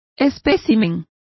Complete with pronunciation of the translation of specimens.